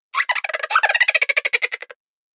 На этой странице собраны разнообразные звуки бурундуков — от веселого стрекотания до любопытного писка.
Редкий писк бурундука